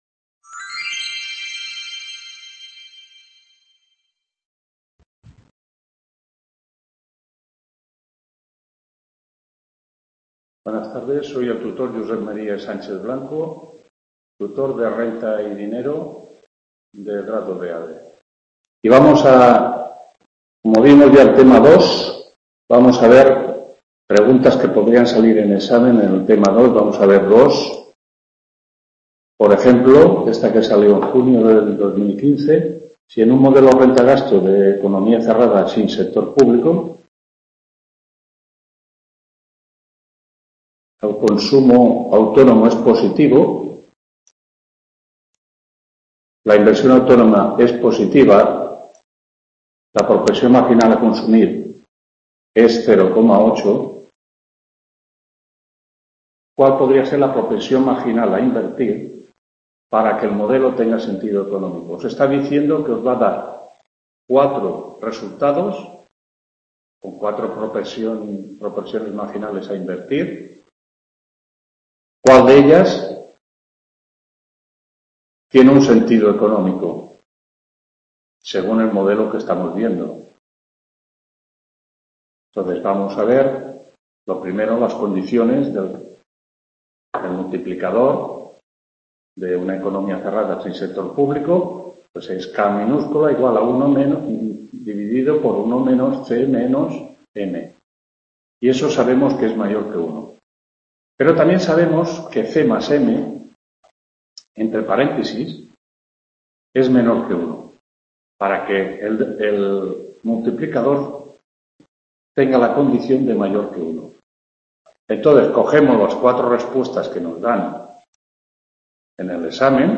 6ª tutoria RENTA Y DINERO TEMA 3 CURVA IS TEMA 4 (I)…